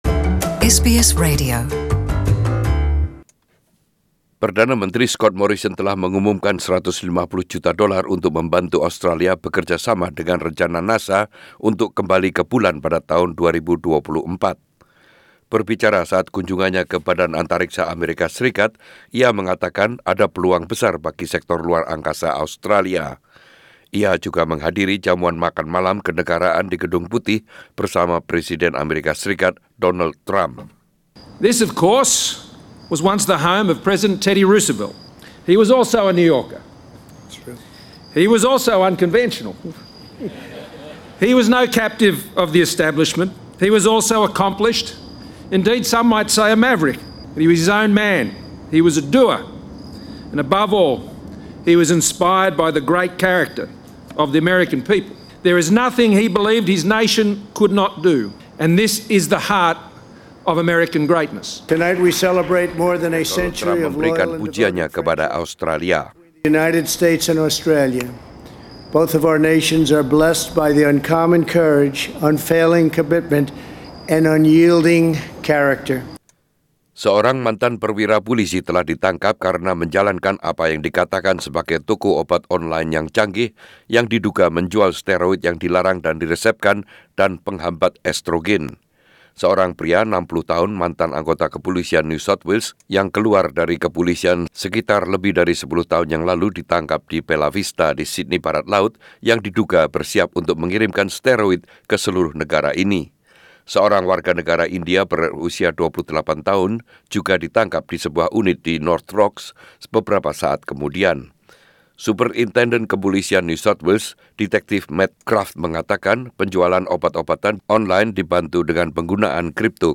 Radio News Bulletin 22 Sep 2019 in Indonesian